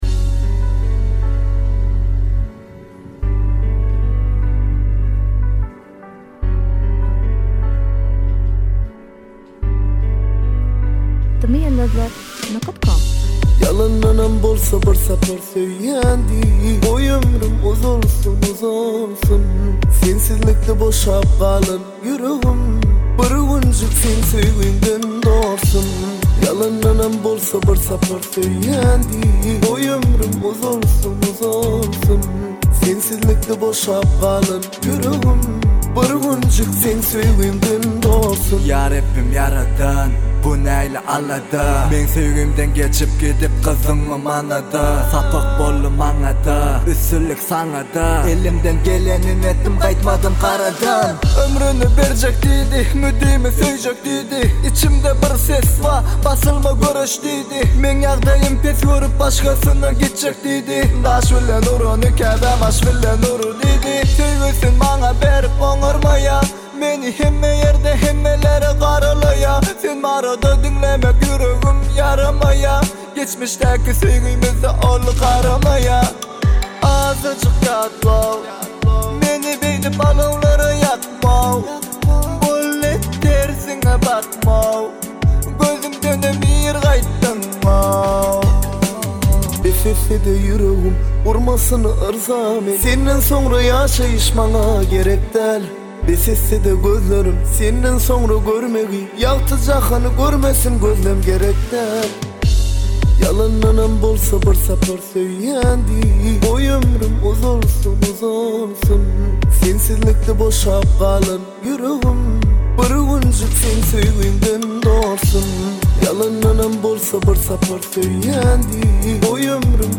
• Альбом: tmrap, aydymlar